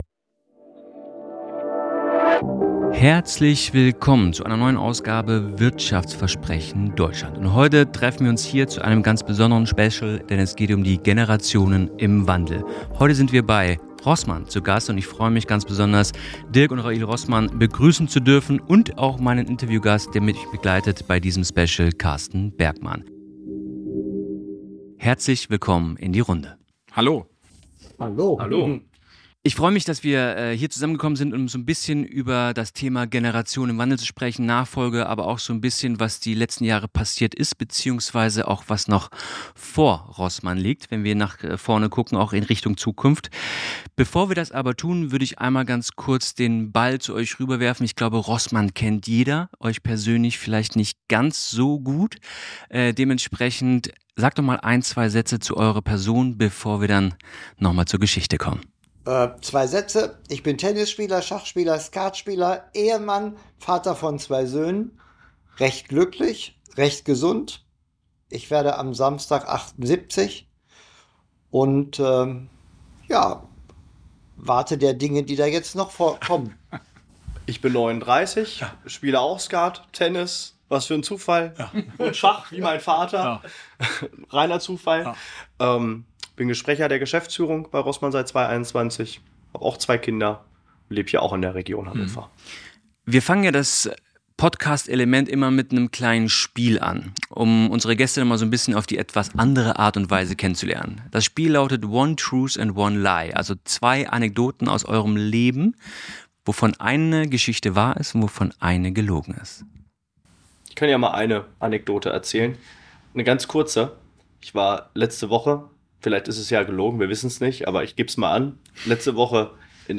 Eine inspirierende Diskussion, die zeigt, wie der Mut zur Veränderung, starke Werte und eine klare Unternehmenskultur der Schlüssel zum nachhaltigen Erfolg sein können.